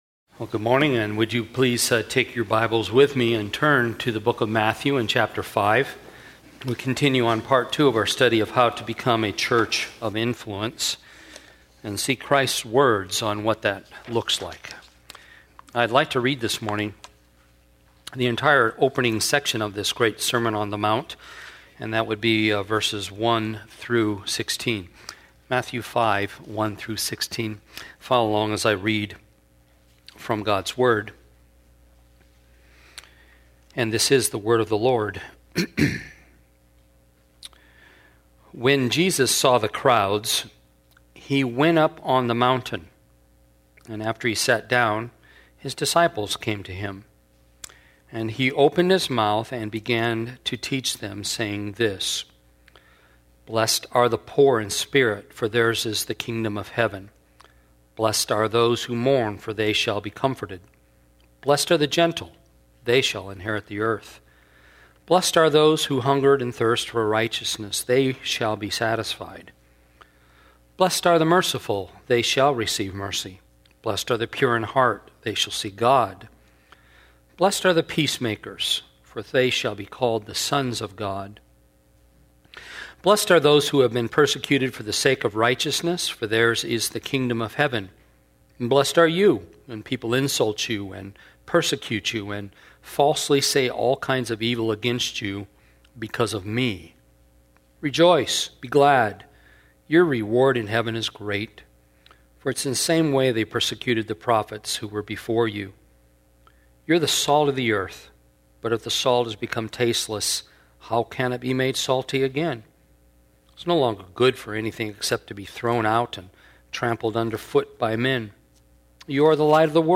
Church of the Canyons - Sermons - Santa Clarita - Evangelical Free